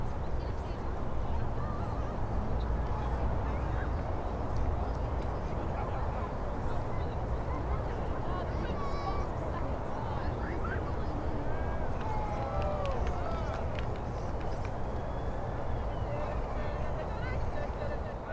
Based on our multiple recordings at Calton Hill, we combined the natural sounds and human activities we collected to recreate and restore the soundscape of the area. We tried to blend distant city ambience with nearby sounds such as footsteps and visitors’ laughter, aiming to build the unique sound texture of Calton Hill through sound.